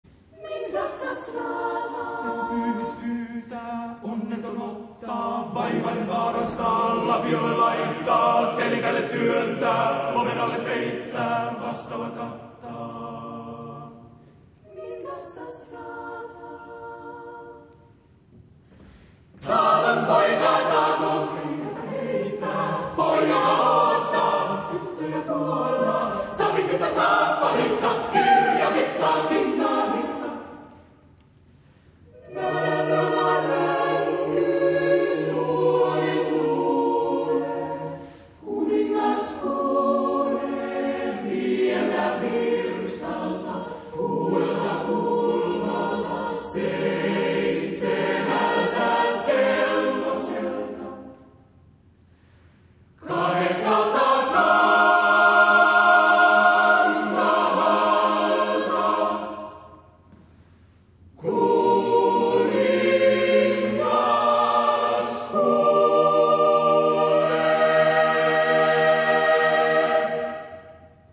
SATB (4 gemischter Chor Stimmen).
Aufnahme Bestellnummer: Internationaler Kammerchor Wettbewerb Marktoberdorf
volume_down Audio von Grex Musicus gesungen Aufnahme Bestellnummer: Internationaler Kammerchor Wettbewerb Marktoberdorf Musica unterstützen Bereichern Einen Fehler melden Karte Nr.104369